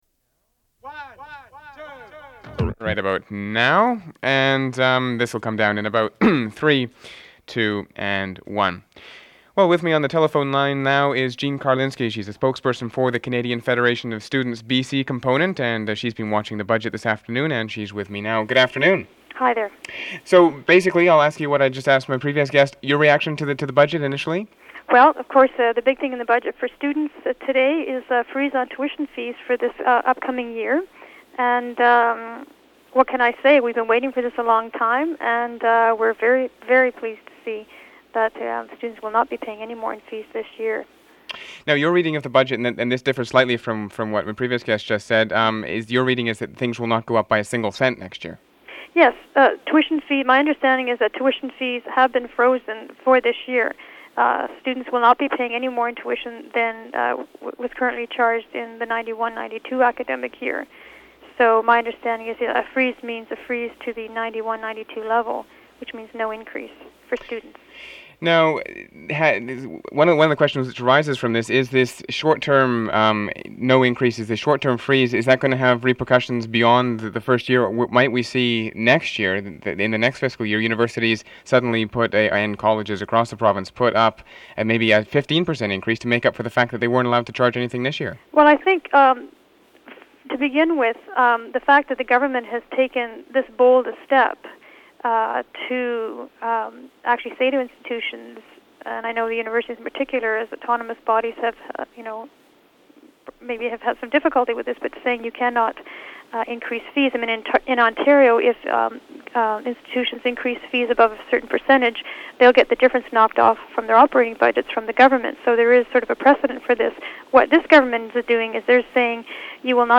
phone interview re. CFS budget